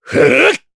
Riheet-Vox_Attack4_jp.wav